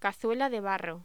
Locución: Cazuela de barro
voz